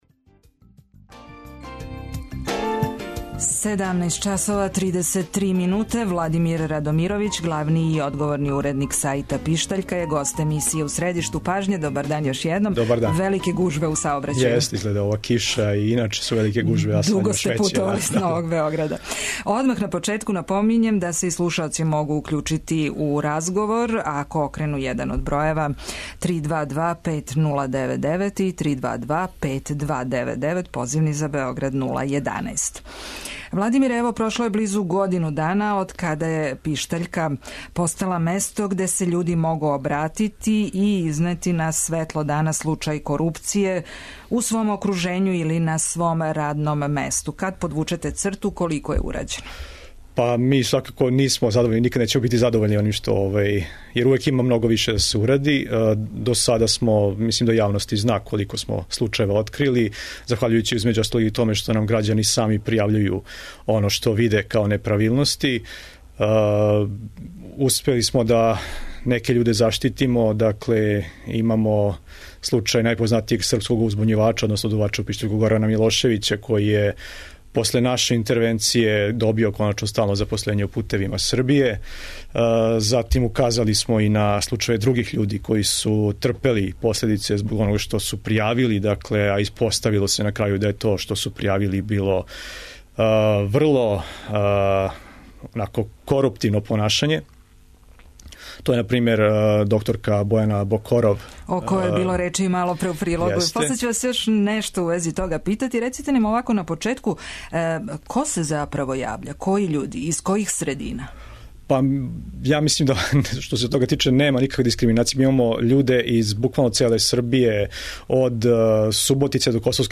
а наши телефони, током емисије, биће отворени за слушаоце који желе да се укључе у разговор.